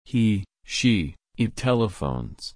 /ˈtɛlɪfəʊn/